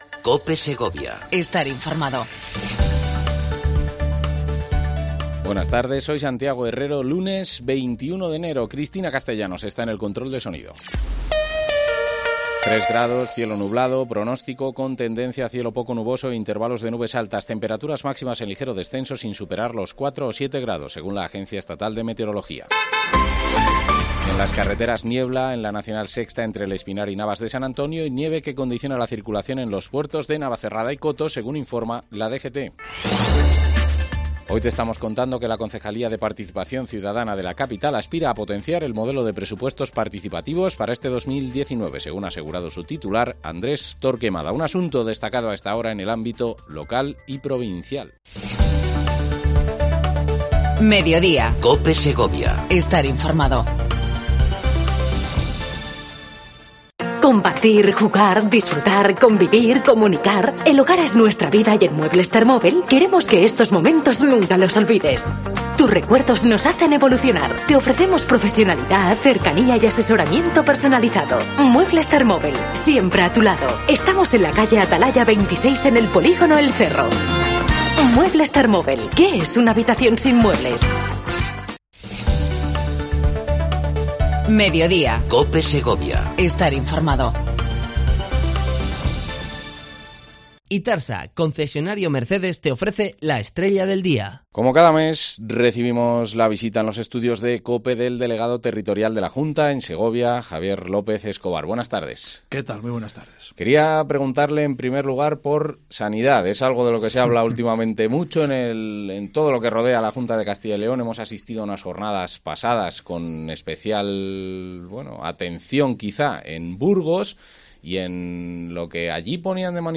Entrevista al Delegado Territorial de la Junta de Castila y Leon en la provincia, Javier López Escobar